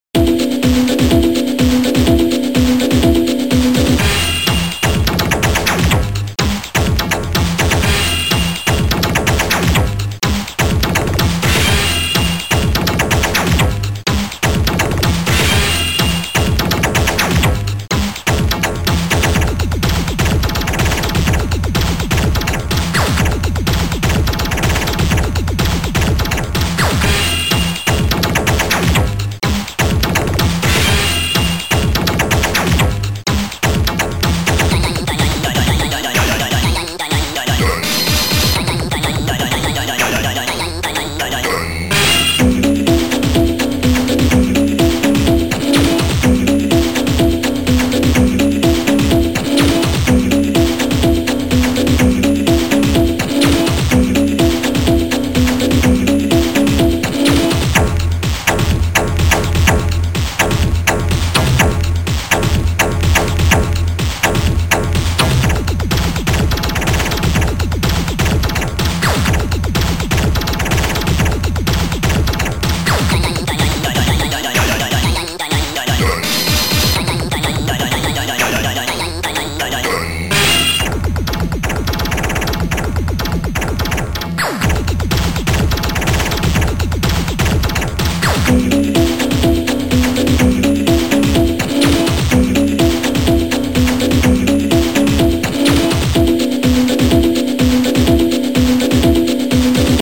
Sound Format: Noisetracker/Protracker
Sound Style: House